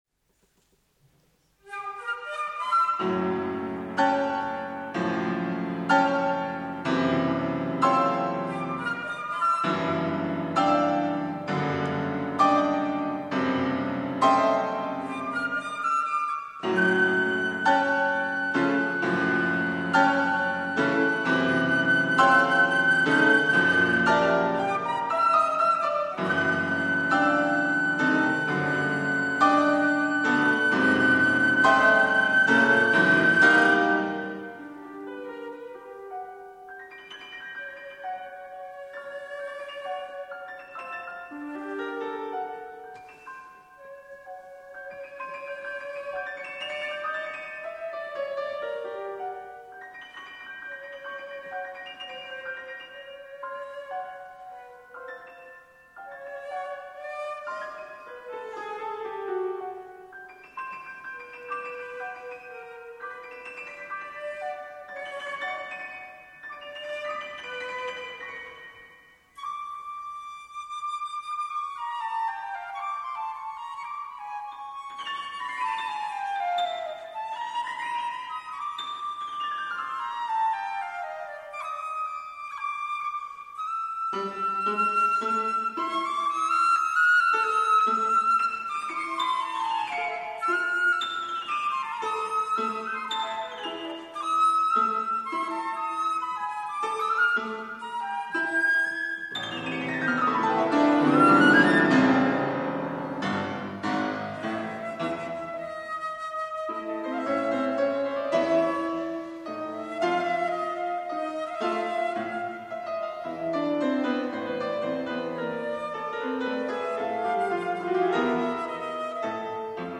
Lookin to the east for flute and piano